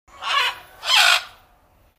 دانلود صدای پرنده 48 از ساعد نیوز با لینک مستقیم و کیفیت بالا
جلوه های صوتی